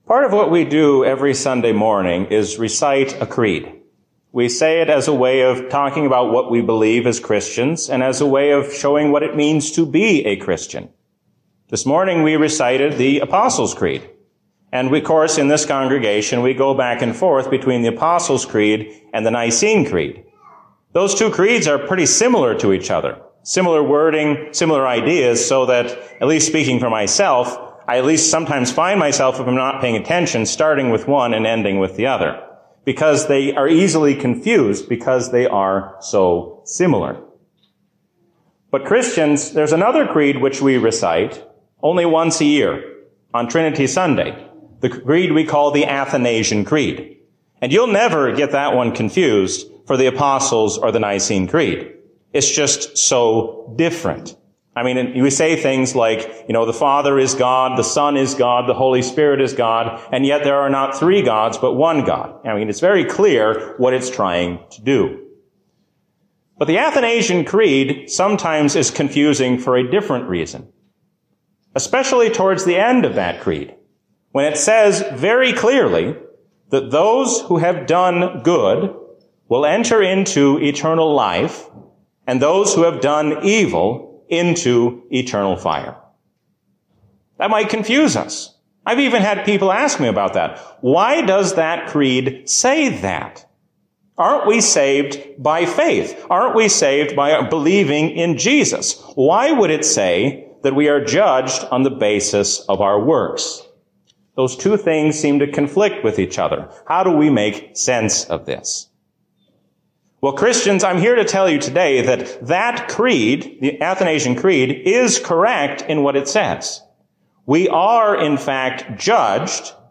A sermon from the season "Trinity 2023." We are called to faithfully carry out the work given to us while we wait for the Last Day.